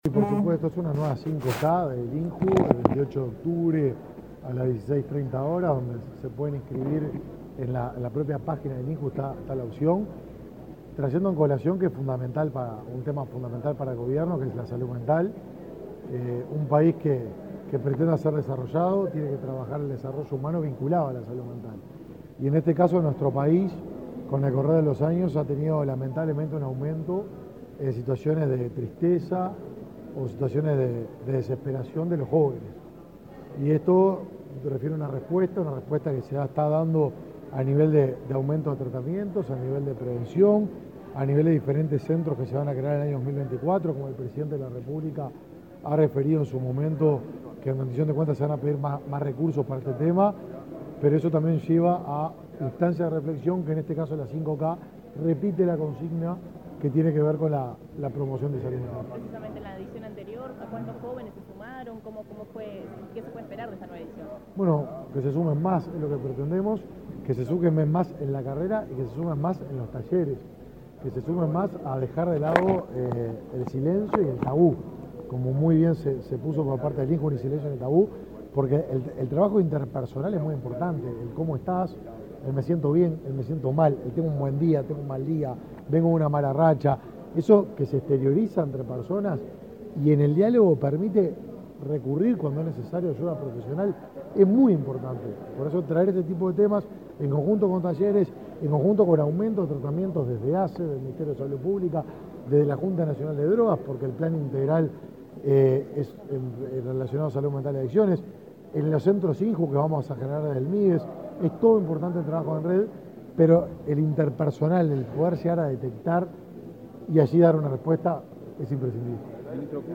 Declaraciones a la prensa del ministro de Desarrollo Social, Martín Lema
Declaraciones a la prensa del ministro de Desarrollo Social, Martín Lema 04/10/2023 Compartir Facebook X Copiar enlace WhatsApp LinkedIn El director del Instituto Nacional de la Juventud (INJU), Felipe Paullier, y el ministro de Desarrollo Social, Martín Lema, participaron en el lanzamiento de la 5K con foco en la estrategia de salud mental Ni Silencio Ni Tabú. Luego el secretario de Estado dialogó con la prensa.